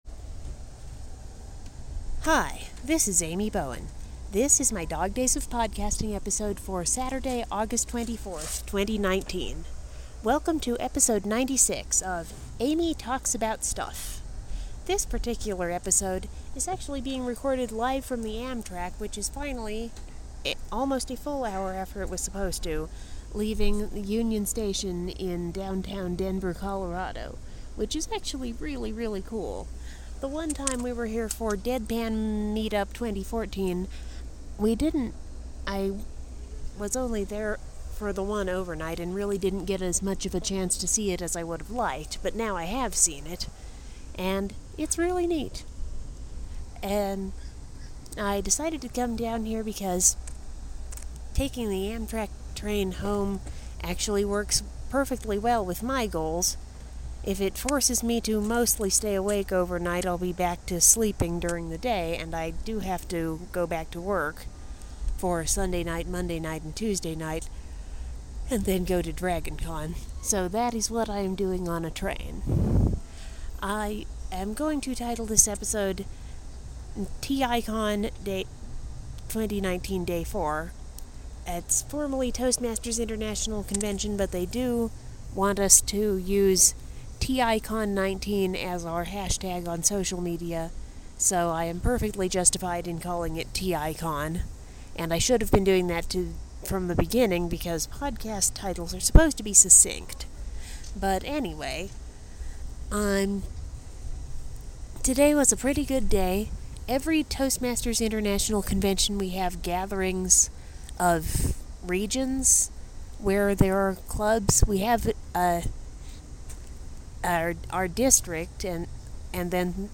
I report on my final day of Toastmasters International Convention (TI Con) 2019, without bothering to add in any theme music.